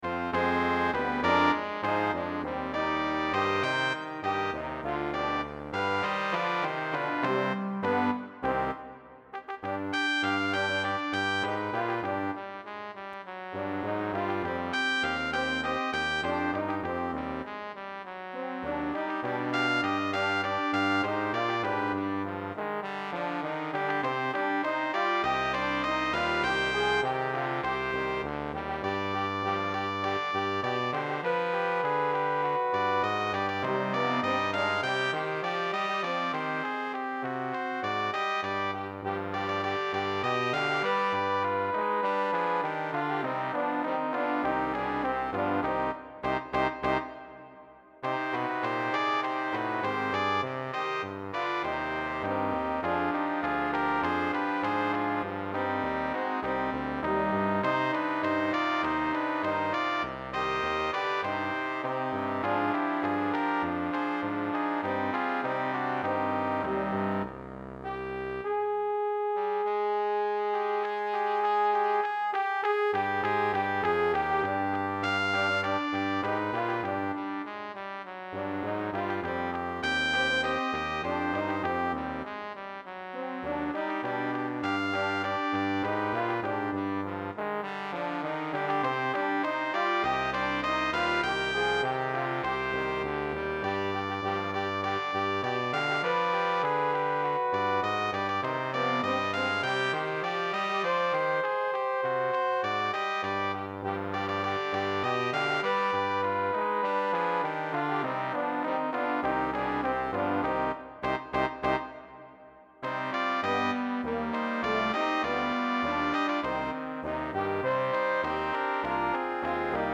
BRASS QUINTET
QUINTETTO - 2 trombe Bb - corno F - trombone - tuba
Demo